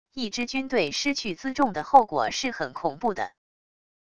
一支军队失去辎重的后果是很恐怖的wav音频生成系统WAV Audio Player